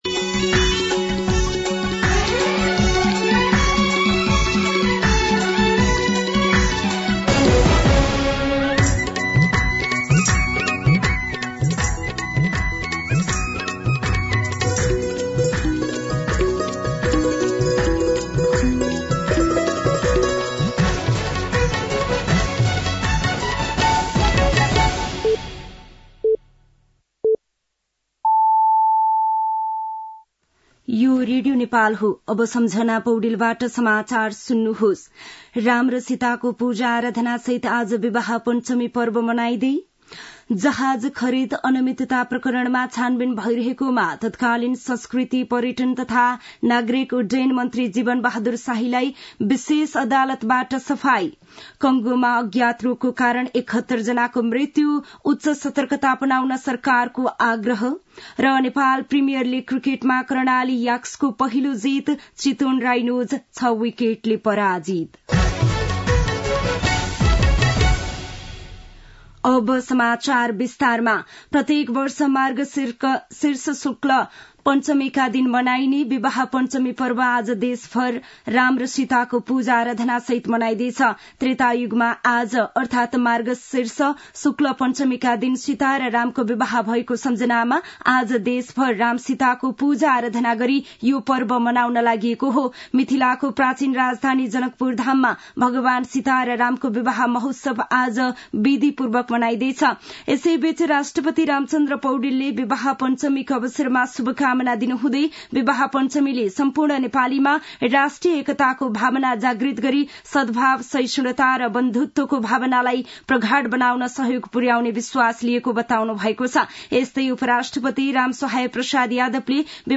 दिउँसो ३ बजेको नेपाली समाचार : २२ मंसिर , २०८१
3pm-News-08-21.mp3